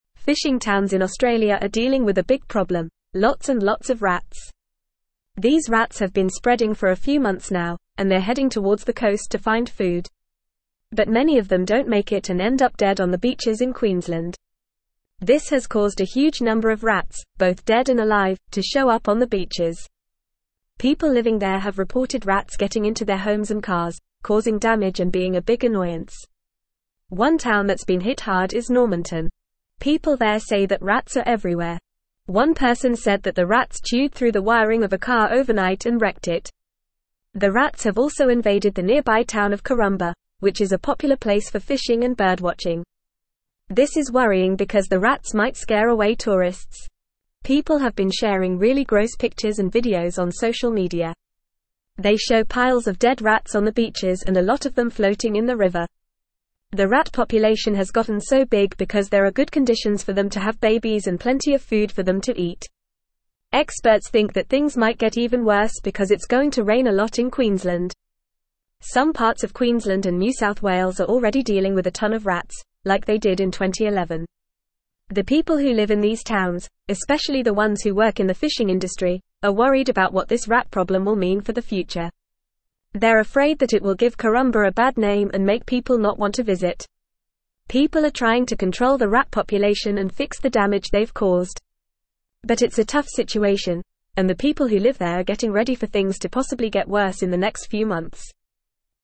Fast
English-Newsroom-Upper-Intermediate-FAST-Reading-Rat-and-Mouse-Plague-Hits-Queenslands-Fishing-Towns.mp3